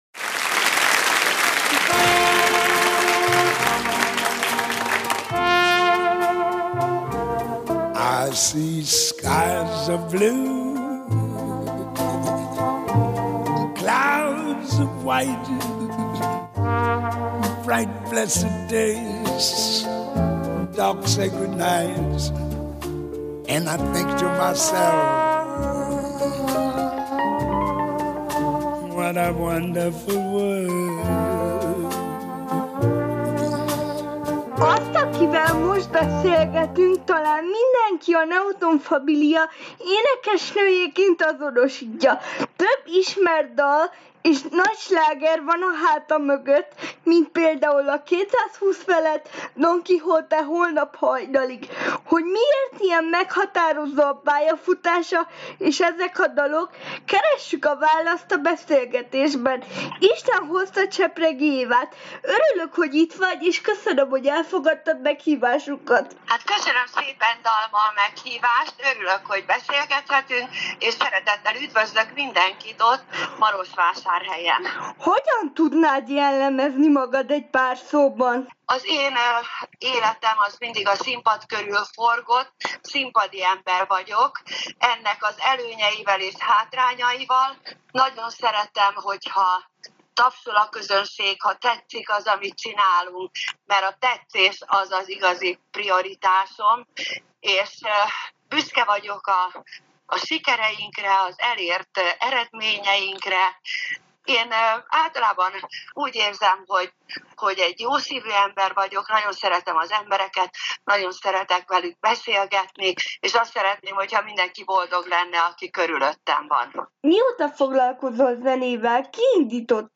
Csepregi Éva énekesnő, dalszövegíró. A Neoton Família sztárjáról kevesen tudják, hogy a Fényszórók alapítvány által támogatja a sérülteket.
Csepregi Éva interjú